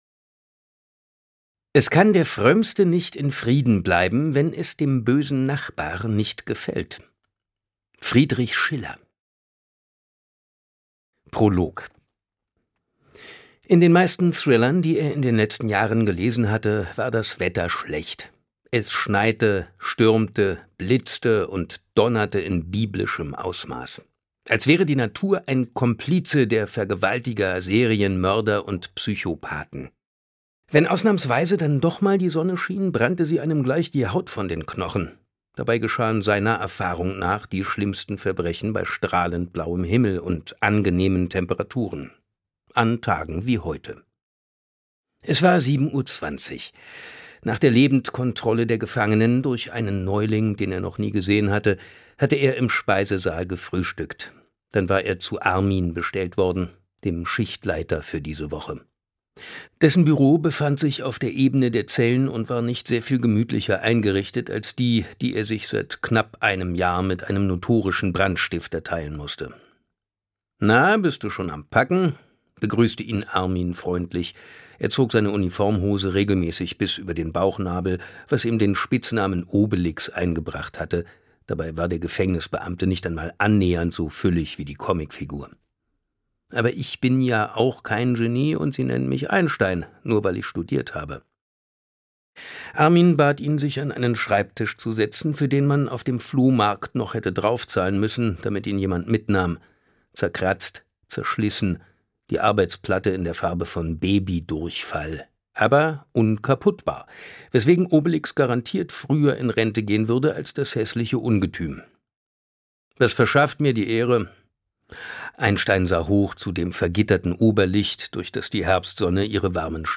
Gruselig-spannend setzt Simon Jäger auch Der Nachbar wieder für alle Fans in Szene.
Gekürzt Autorisierte, d.h. von Autor:innen und / oder Verlagen freigegebene, bearbeitete Fassung.
Der Nachbar Gelesen von: Sebastian Fitzek, Simon Jäger
• Sprecher:innen: Simon Jäger